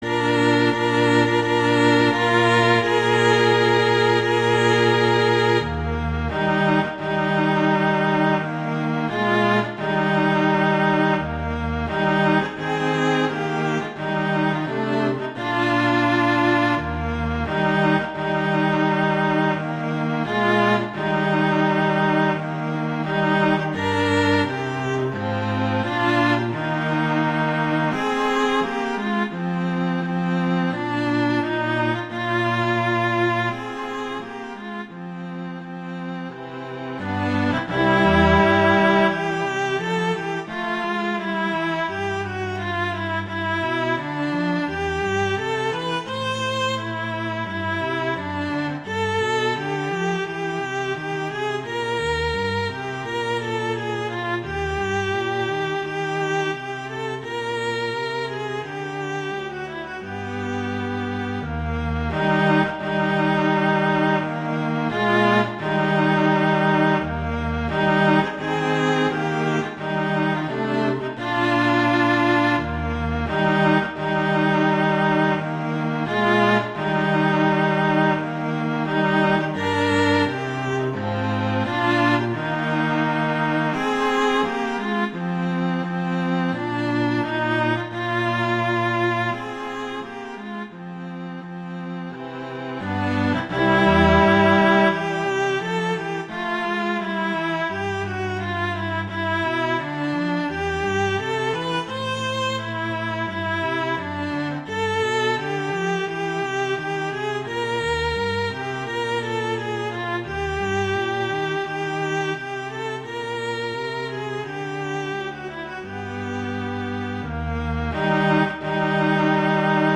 Instrumentation: viola & cello
arrangements for viola and cello